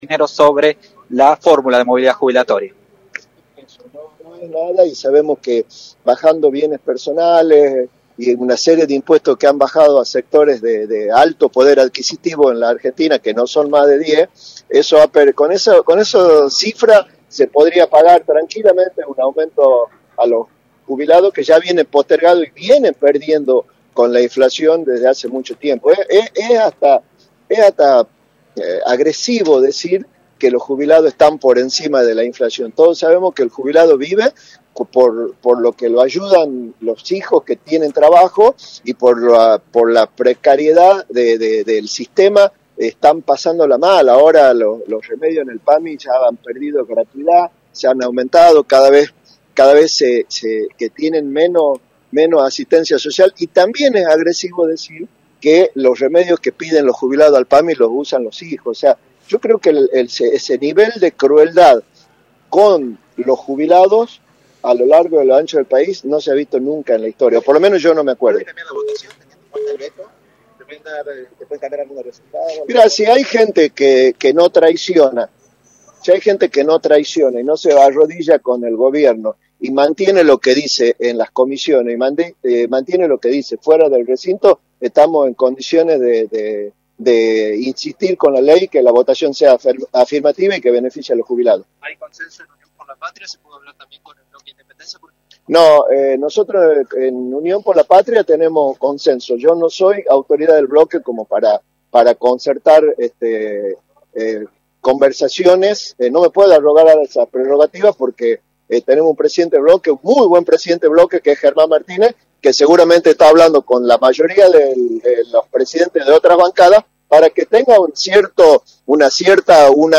“Los jubilados vienen perdiendo con la inflación desde hace tiempo, es hasta agresivo decir que los jubilados están por encima de la inflación cuando todos sabemos que los jubilados viven por la ayuda de sus hijos y sufren la precariedad del sistema, por eso ese nivel de crueldad con los jubilados no se vio nunca en la historia” señaló Cisneros en “La Mañana del Plata”, por la 93.9.